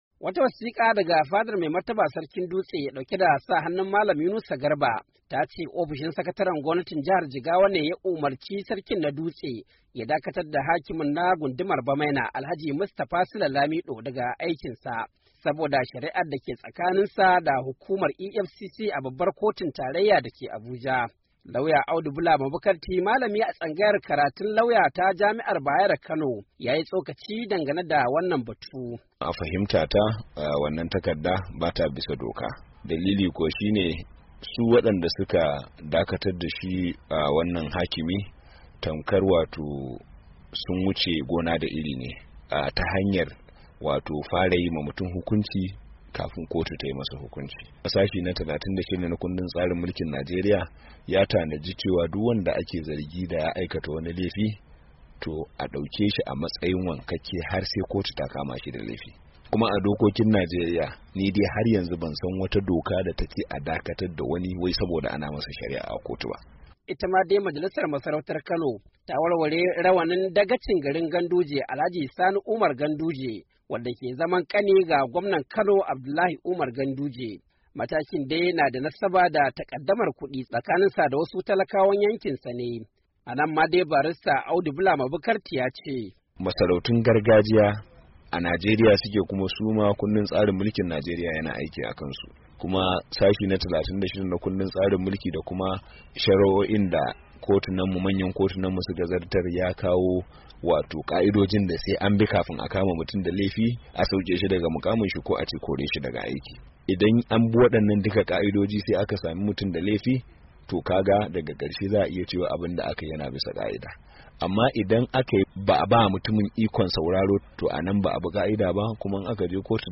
ciki har da tsokaci da masana shari’a suka yi dangane da wadannan matakan: